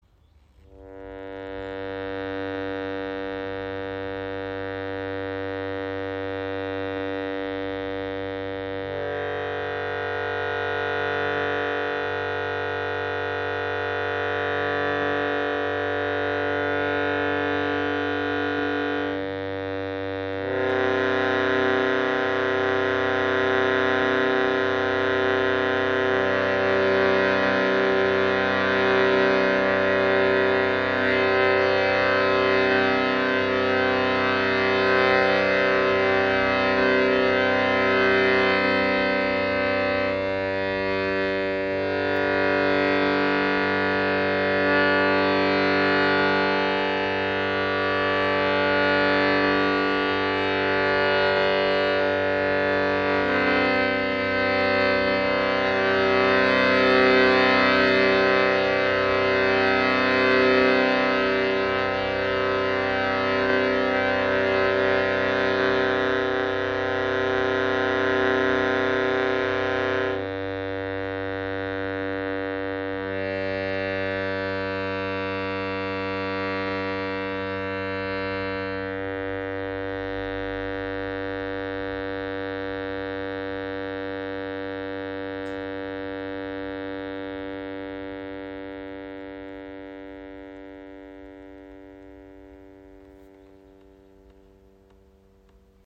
RADHA Shruti Box | 2. Oktaven | Tonumfang G2–F#4 | in 440 oder 432 Hz
• Icon Besonders gleichmässiger Klangfluss dank doppeltem Blasebalg.
Die tiefere Oktave (G2–F#3) liefert warme, erdige Klänge – ideal für Meditation und tiefe Stimmen. Die höhere Oktave (G3–F#4) dagegen bringt helle, leichte Töne hervor, die besonders gut zu helleren Stimmen oder Obertongesang passen.